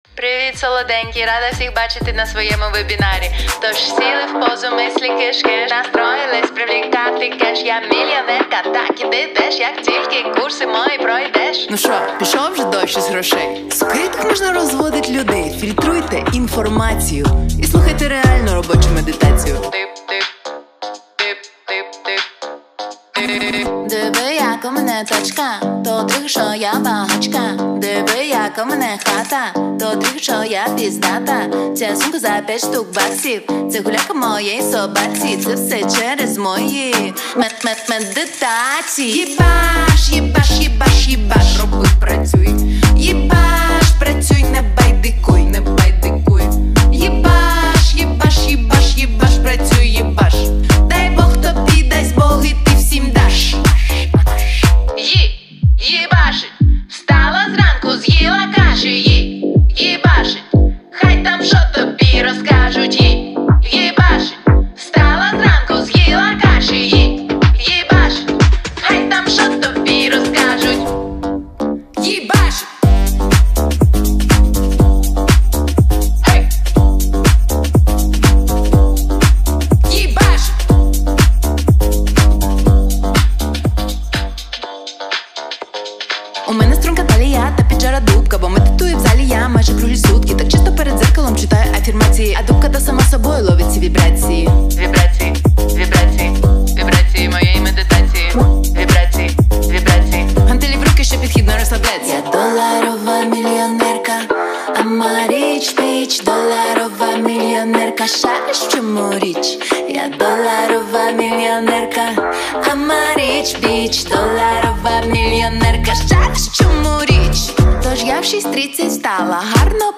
• Жанр:Реп